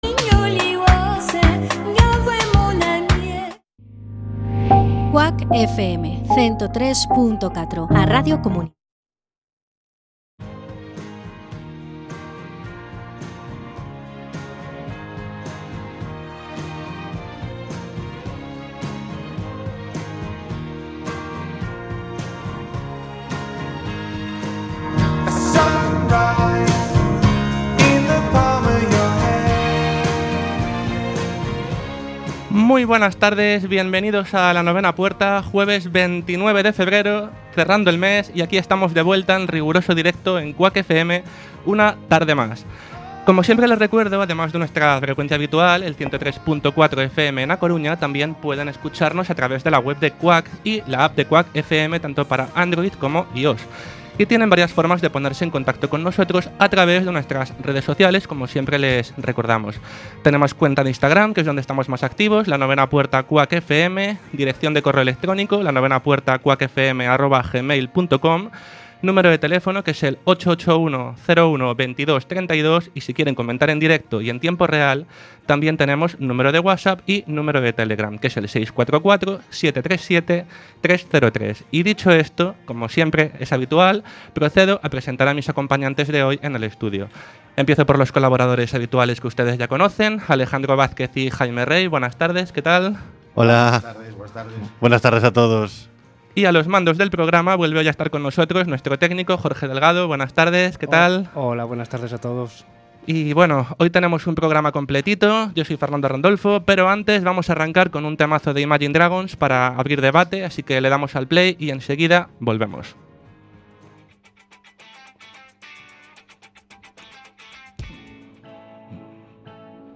Programa de opinión y actualidad en el que se tratan temas diversos para debatir entre los colaboradores, con algún invitado relacionado con alguno de los temas a tratar en el programa y que además cuenta con una agenda de planes de ocio en la ciudad y alguna recomendación musical y de cine/series/libros.